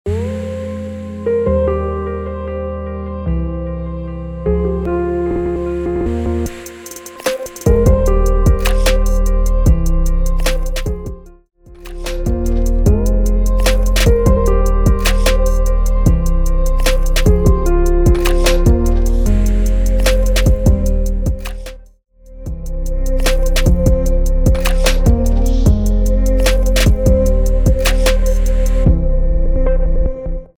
150 BPM Hip Hop – Hip Hop
Cool
Hip Hop